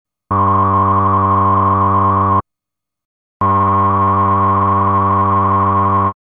Фильтр сабжа может очень близко к железке звучать, если его постараться подогнать.
Вложения Jun-60 filter.mp3 Jun-60 filter.mp3 245,7 KB · Просмотры: 684